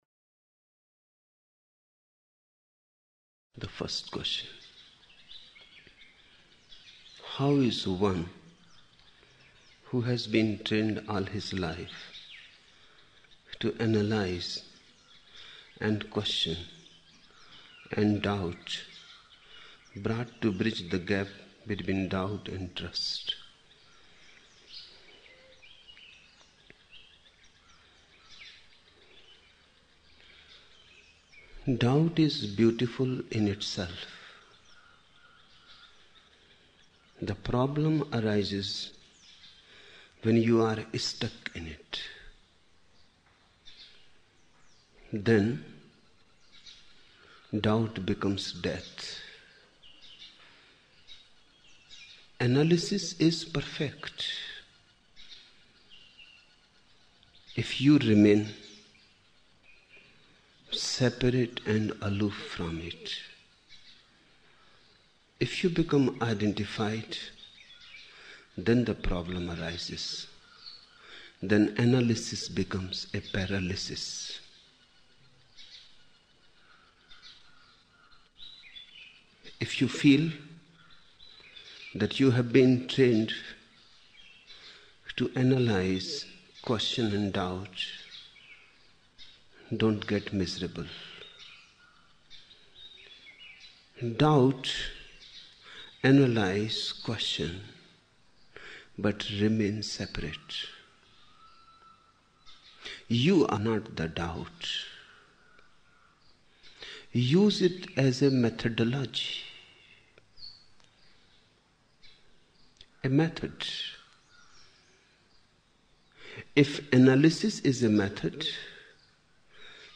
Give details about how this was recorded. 28 October 1975 morning in Buddha Hall, Poona, India